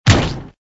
MG_cannon_hit_tower.ogg